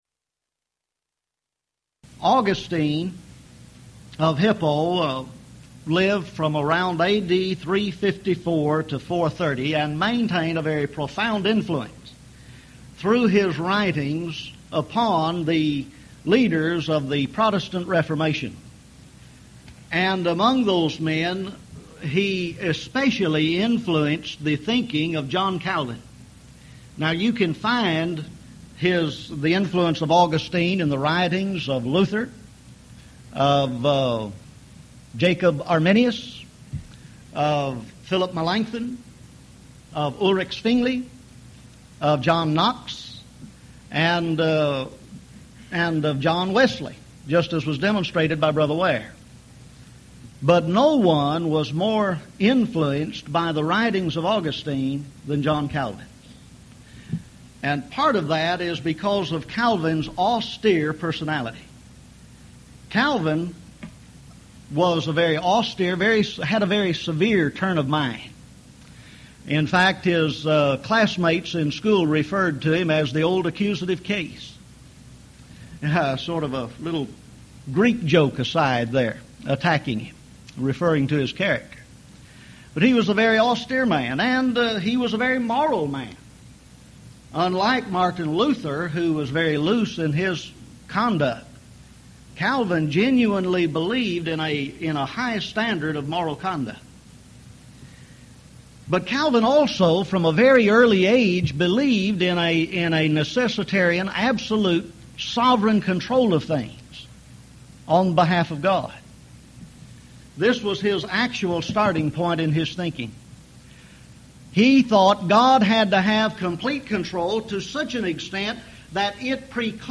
Event: 1998 Houston College of the Bible Lectures
lecture